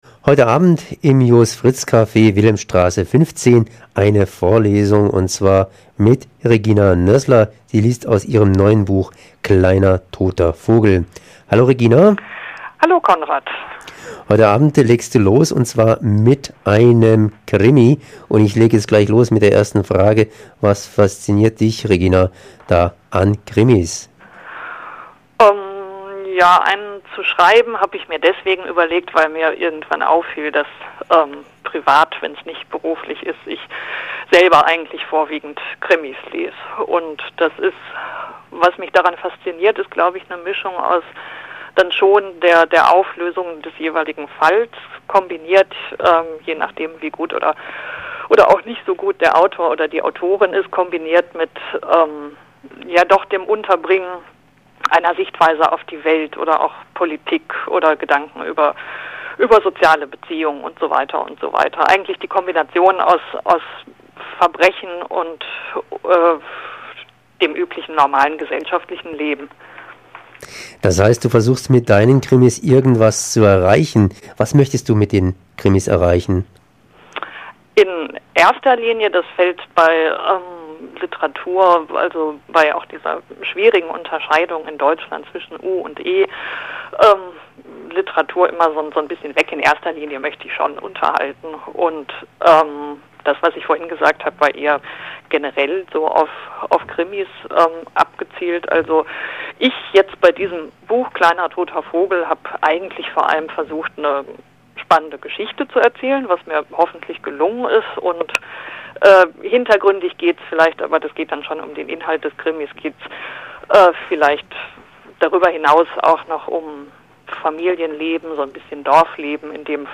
Krimi-Lesung: Kleiner toter Vogel